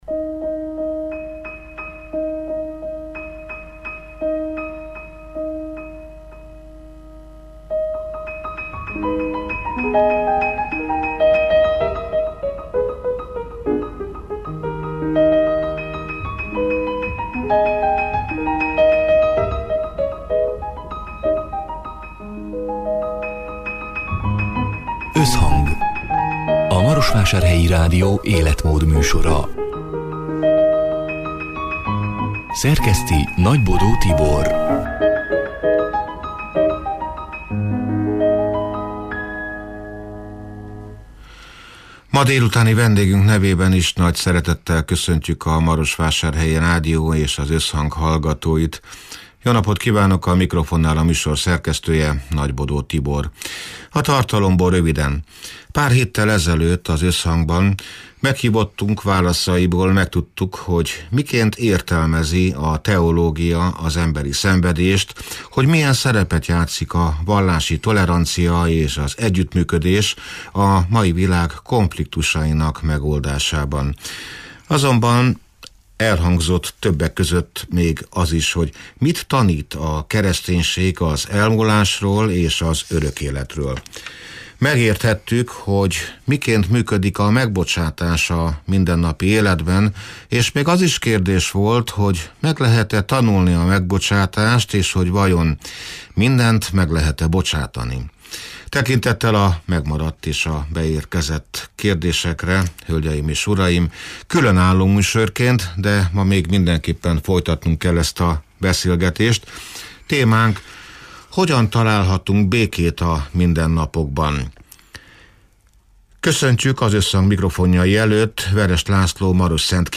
(elhangzott: 2024. november 13-án, szerdán délután hat órától élőben)